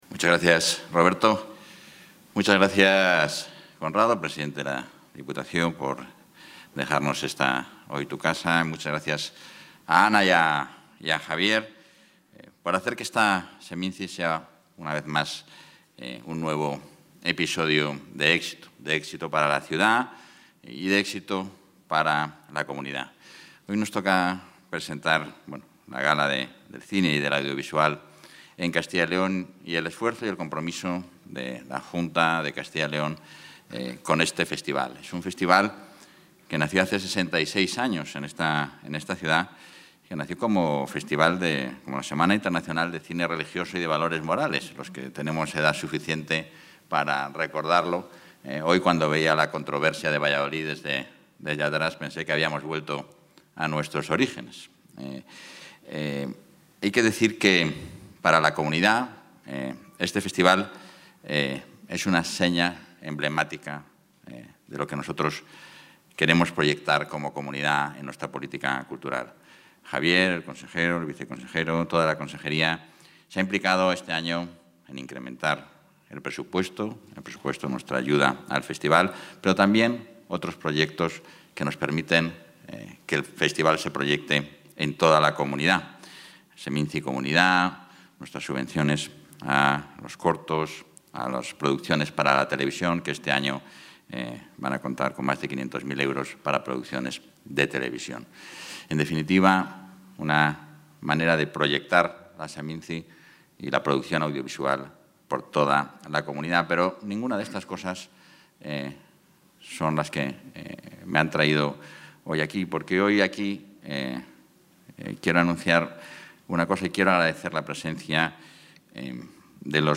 Intervención del vicepresidente de la Junta.
El vicepresidente, portavoz y consejero de Transparencia, Ordenación del Territorio y Acción Exterior, Francisco Igea, ha acudido esta tarde a la gala del Día del Cine y del Audiovisual que se celebra en el marco de la Semana Internacional de Cine de Valladolid, SEMINCI, en el Teatro Zorrilla.